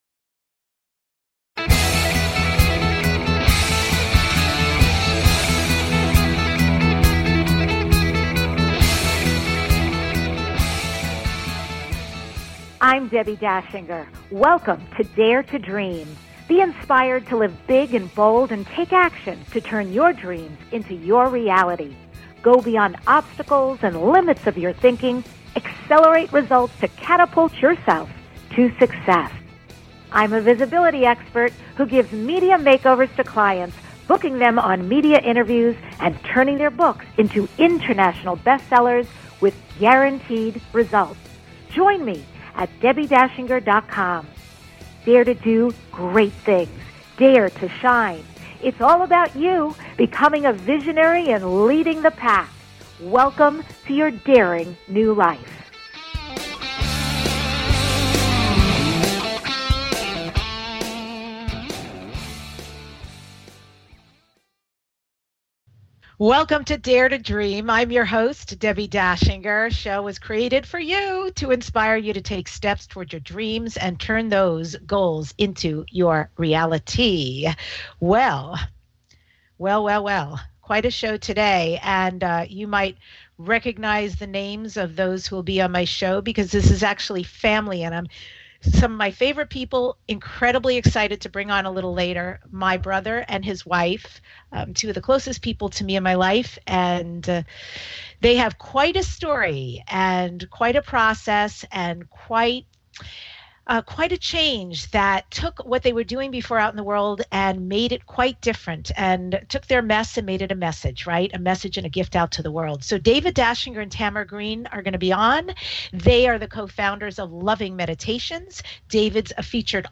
Dare To Dream Talk Show